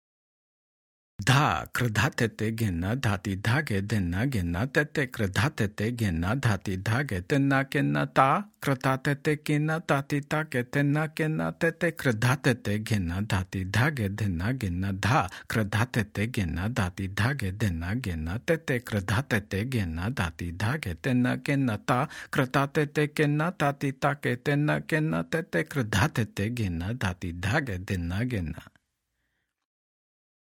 Demonstrations
Spoken – Medium